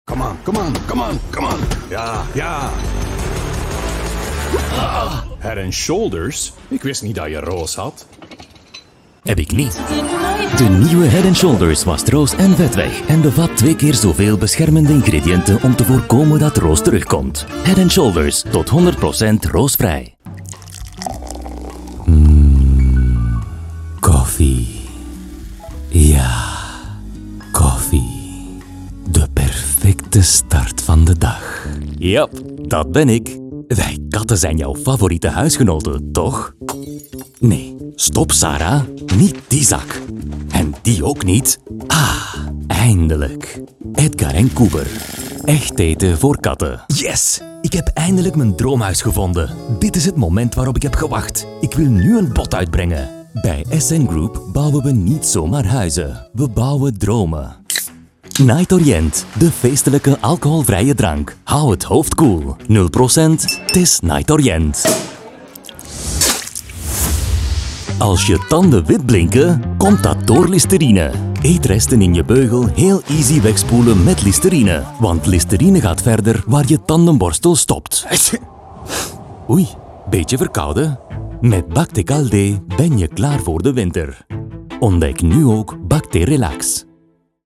Male
Adult (30-50)
Flemish voice talent, characterized by a warm and friendly voice with clear articulation, creating the right atmosphere and achieving the perfect tone for each project.
Words that describe my voice are warm, deep, corporate.
0703FLEMISH_Commercial.mp3